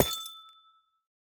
Amethyst break2.ogg.mp3
Amethyst_break2.ogg.mp3